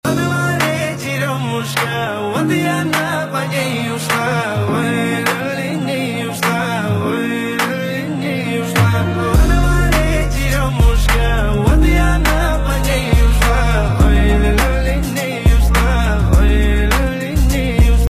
• Качество: 192, Stereo
красивый мужской голос
русский рэп
спокойные